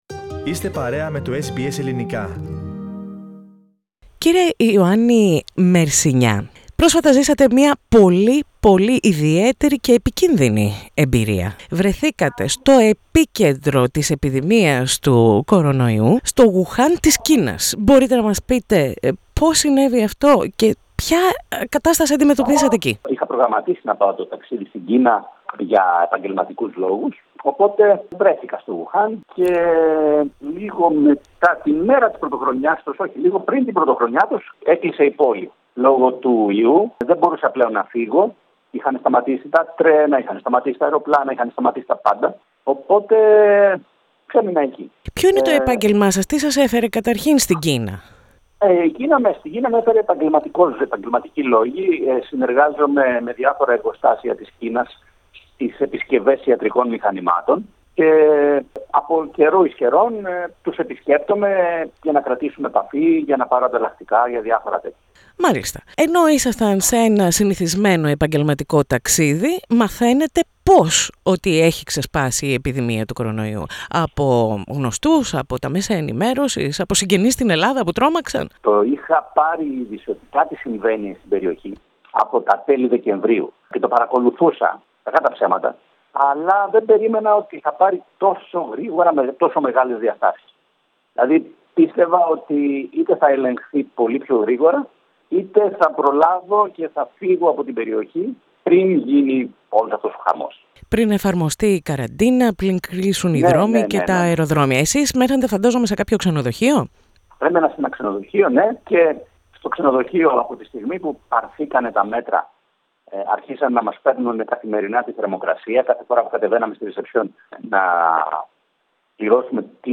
Greek man evacuated from Wuhan speaks to SBS
Through the quarantine in "Sotiria" hospital, he spoke about his experience at SBS Greek radio program.